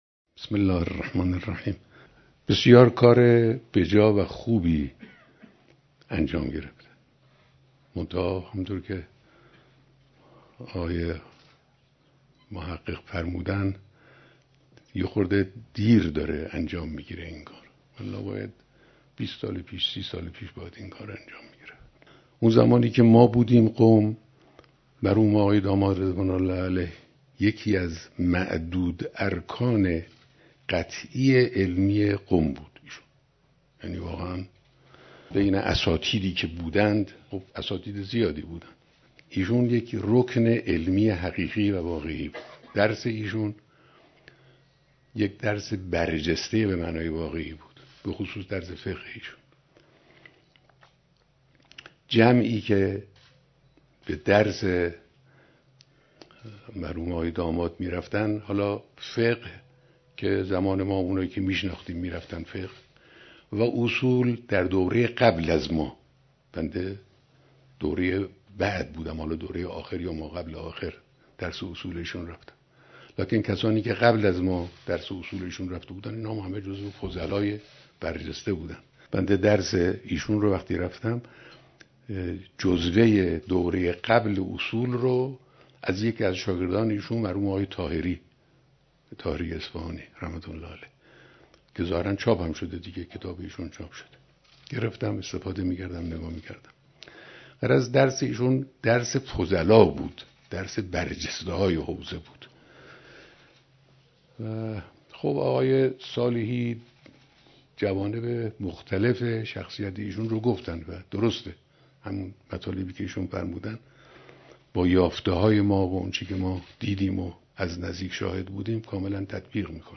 بیانات در دیدار اعضای ستاد همایش نکوداشت مرحوم آیت الله محقق داماد